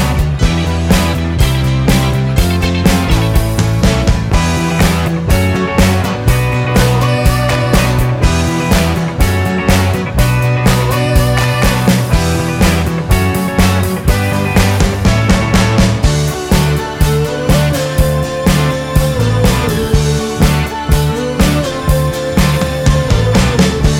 End Cut Down Pop (1970s) 3:59 Buy £1.50